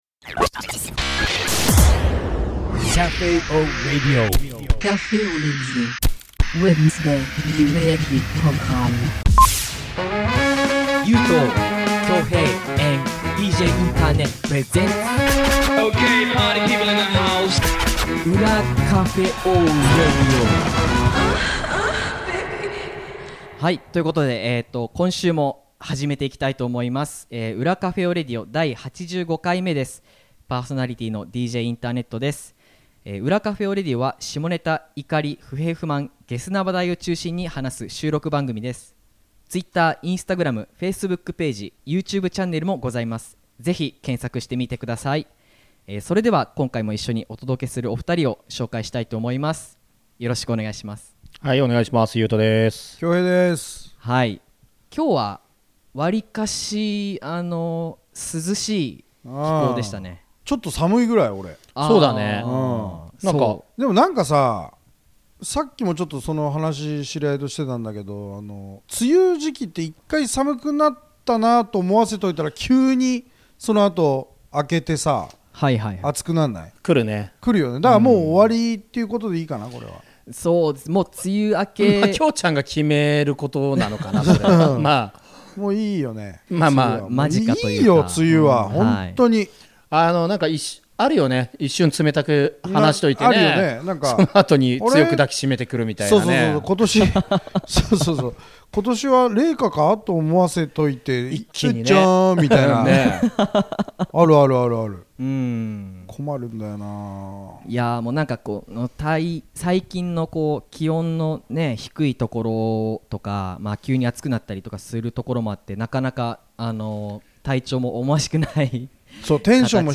3人で話してみました！